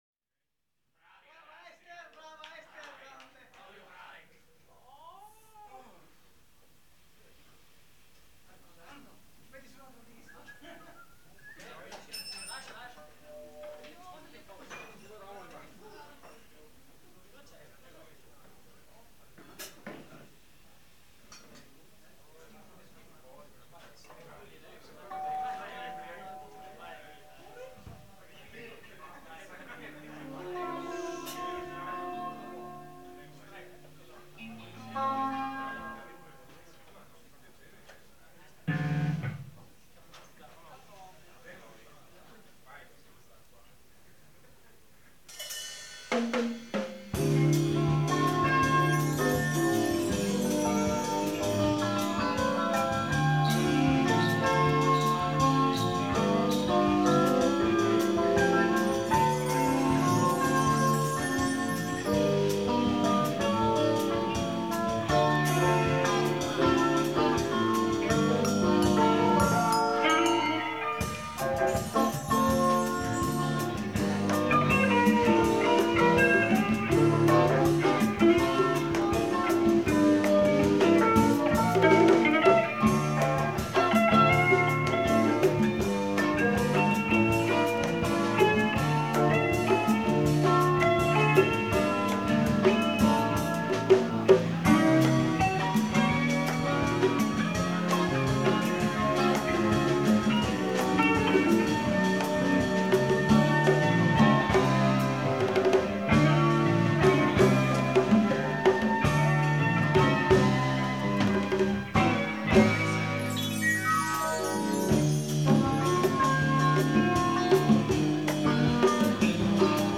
1986 - JAZZ ROCK FUSION - LIVE AT VILLA CLASTA - FERRARA
bass
guitar
keyboards
drums
percussion